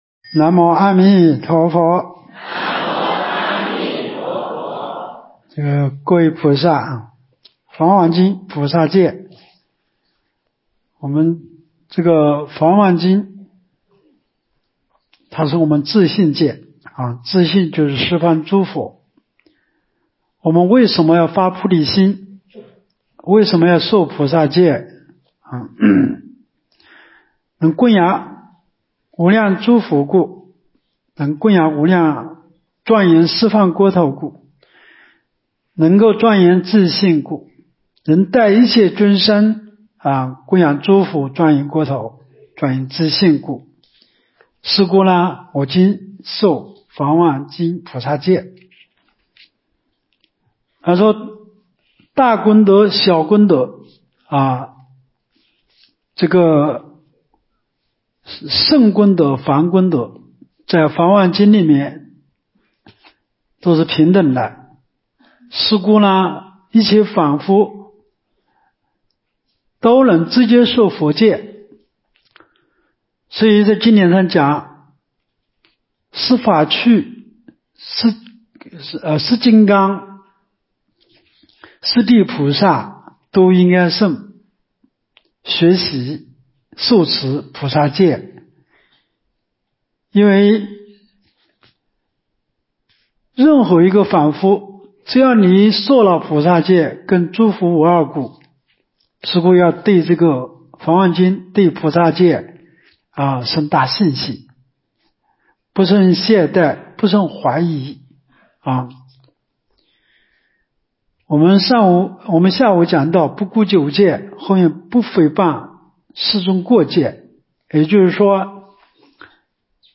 2024冬季佛七三皈五戒菩萨戒开示（2）